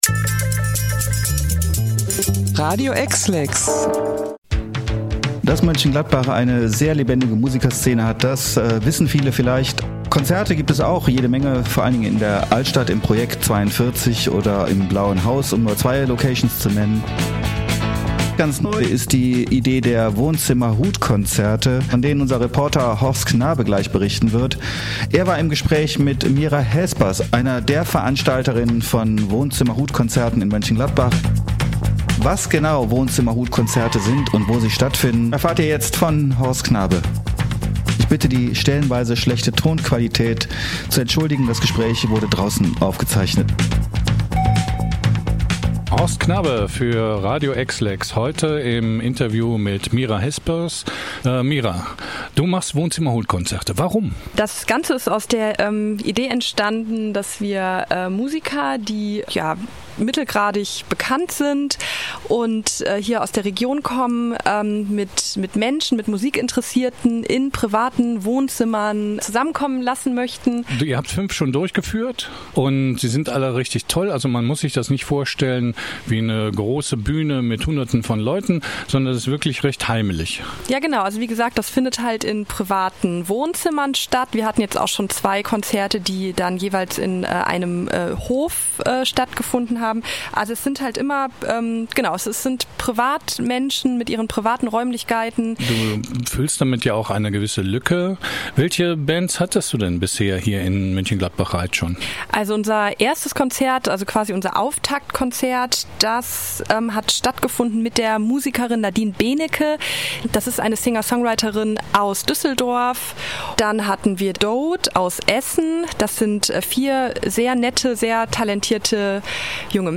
Wohnzimmerhutkonzerte in Gladbach – Interview